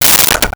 Plastic Bowl 02
Plastic Bowl 02.wav